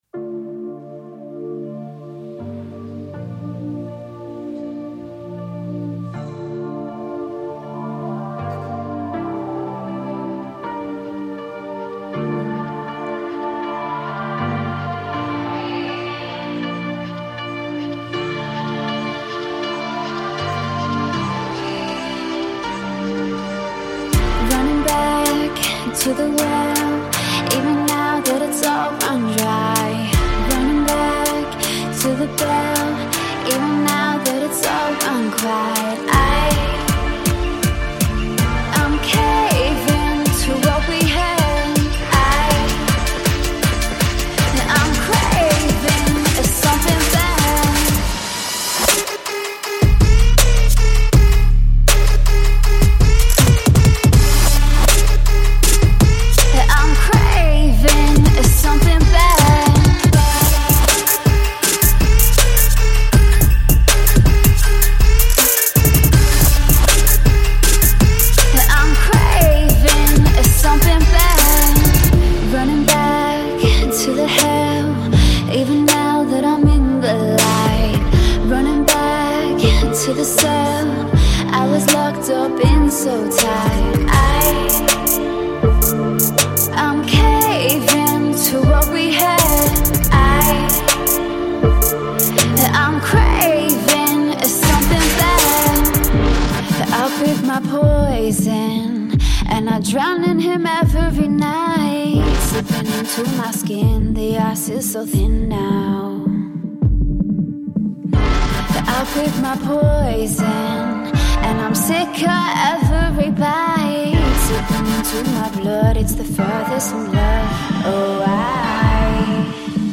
# Trap